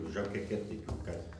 Catégorie Locution ( parler, expression, langue,... )